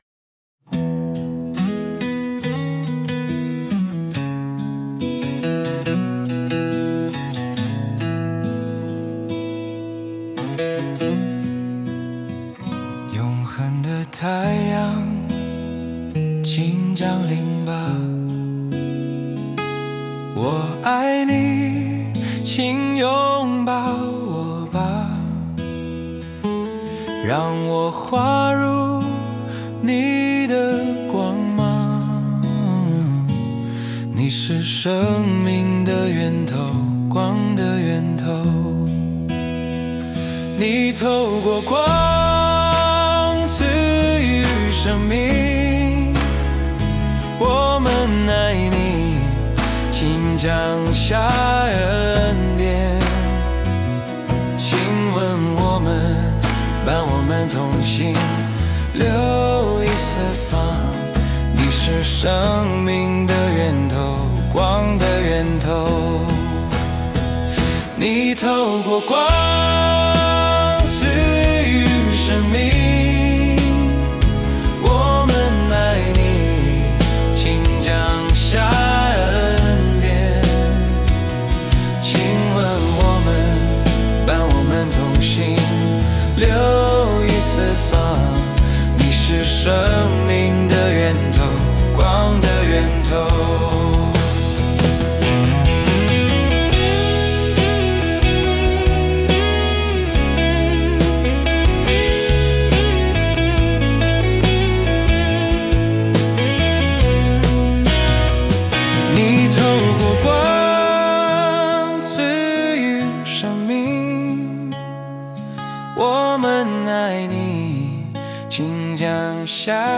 我以此为歌词，用AI创建了两首歌曲，我自己颇为喜爱。